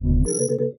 ui_open.mp3